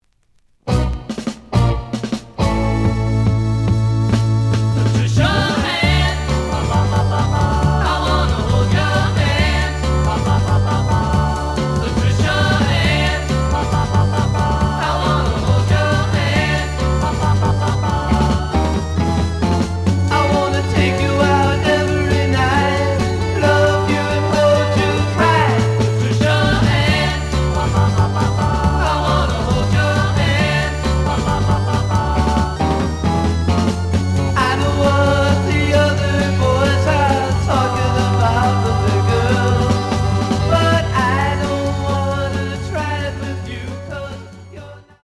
試聴は実際のレコードから録音しています。
●Genre: Rock / Pop
●Record Grading: EX- (B面のラベルに若干のダメージ。多少の傷はあるが、おおむね良好。)